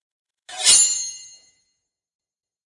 Suara Pedang Samurai Dicabut
Kategori: Suara senjata tempur
Keterangan: Suara pedang Samurai dicabut kini dapat digunakan untuk berbagai keperluan, seperti mengedit video atau sebagai nada dering WA.
suara-pedang-samurai-dicabut-id-www_tiengdong_com.mp3